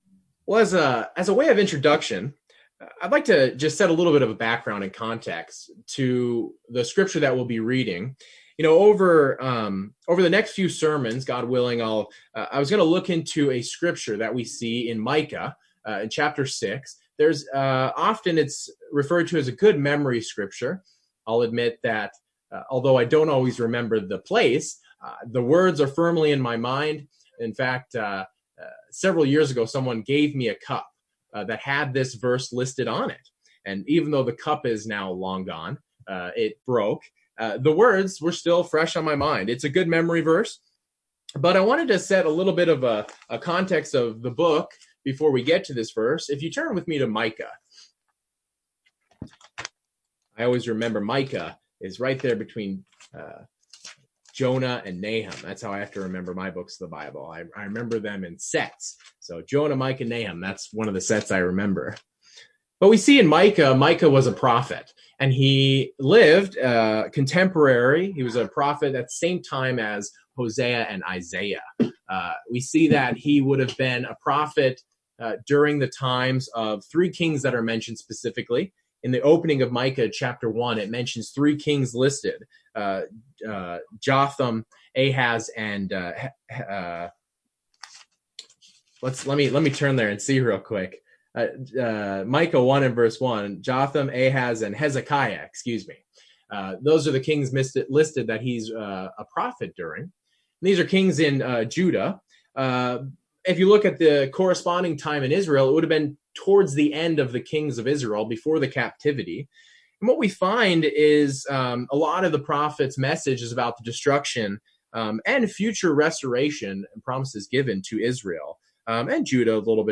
The Lord requires of His people to do justly, love mercy, and walk humbly with Him. In this first part in a series on the Lord's requirement, the sermon will examine the statement to do justly.